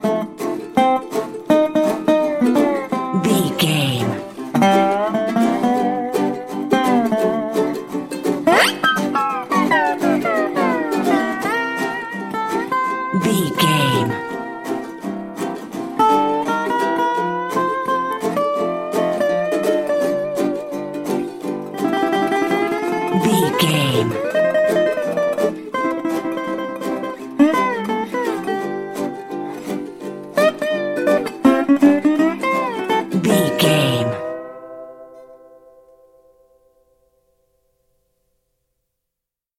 Ionian/Major
acoustic guitar
banjo
percussion
ukulele
slack key guitar